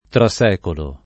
trasecolo [ tra S$ kolo ]